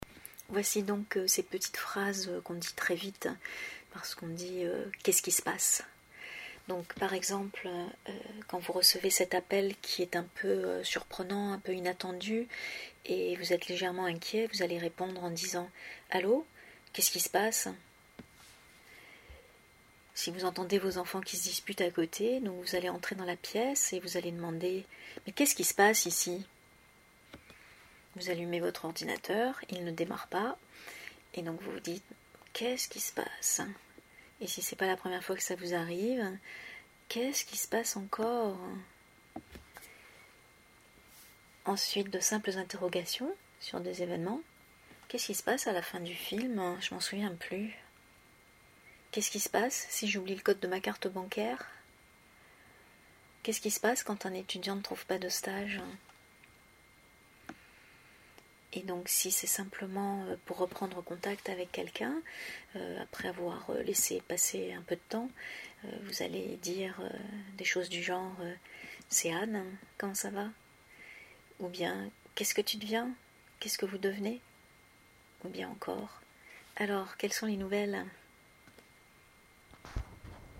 Voici ces petites phrases enregistrées, à la vitesse où on les dit :